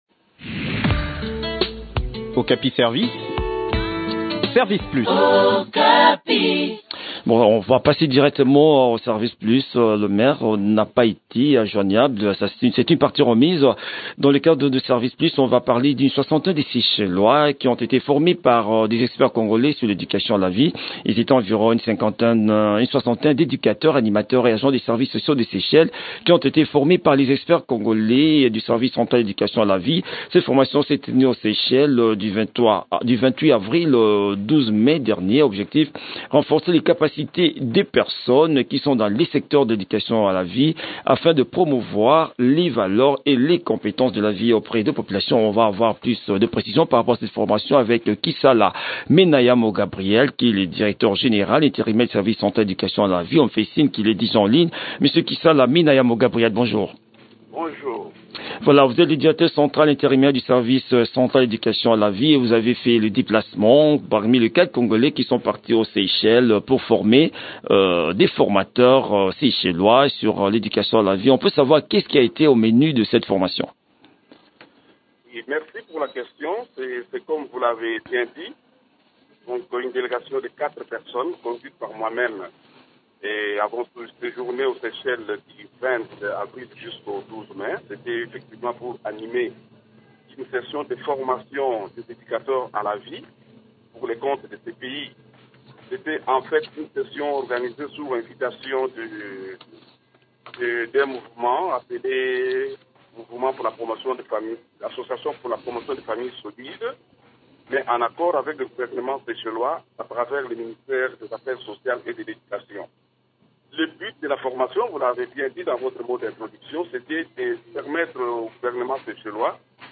Le point sur l’organisation de cette session de formation dans cet entretien